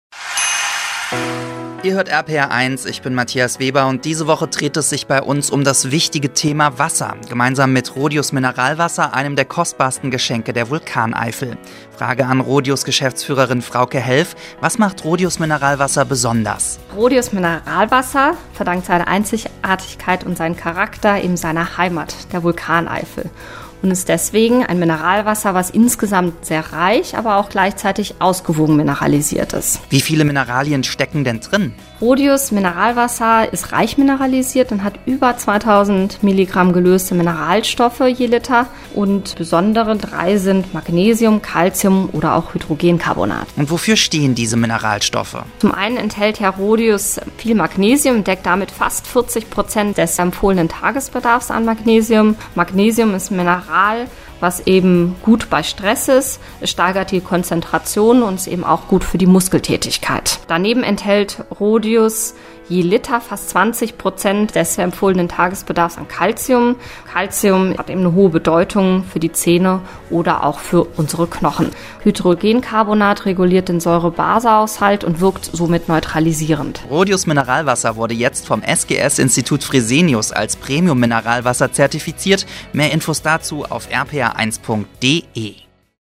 On air
Diese Infomercials im Interviewstil transportieren den erklärungsbedürftigen Inhalt rund um das Produkt, das neue Fresenius-Siegel und die Firmenphilosophie von RHODIUS.